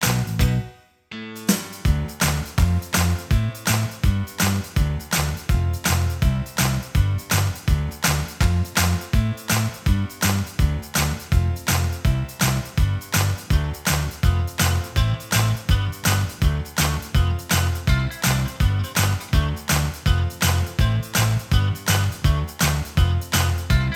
Minus All Guitars Pop (1990s) 3:24 Buy £1.50